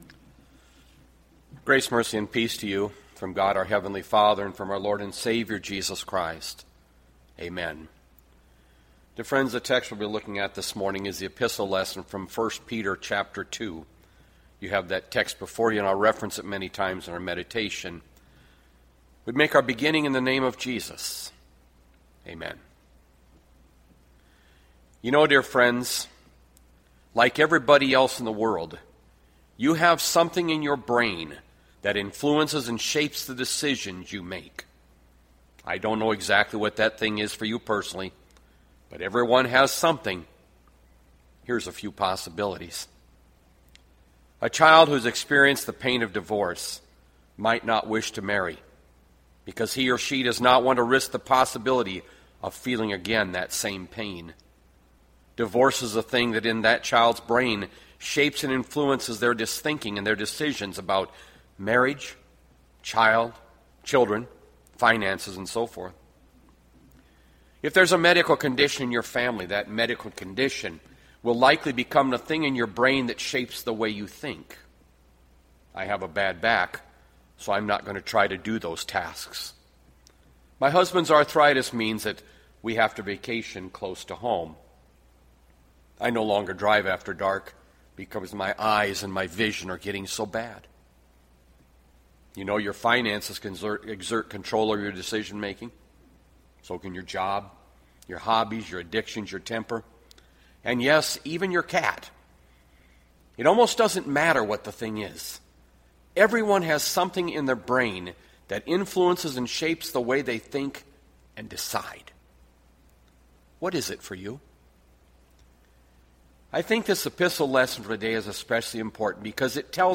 Bethlehem Lutheran Church, Mason City, Iowa - Sermon Archive May 3, 2020